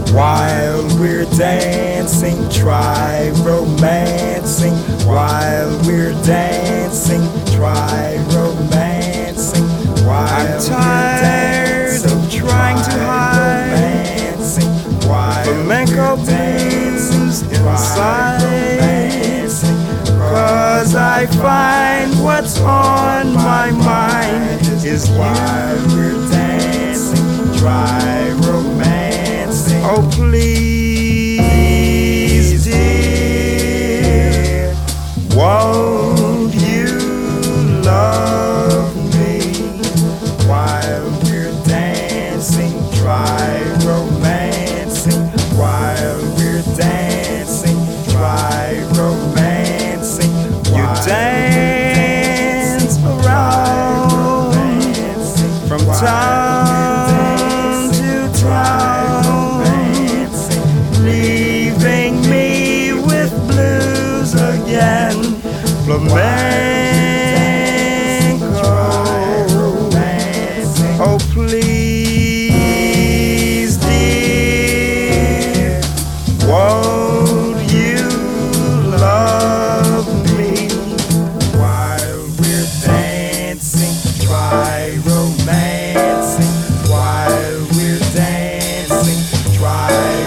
JAZZ / JAZZ VOCAL / POETRY READING
黒さ溢れるポエトリー・ジャズ・ヴォーカル！